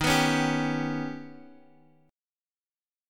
Eb+7 Chord
Listen to Eb+7 strummed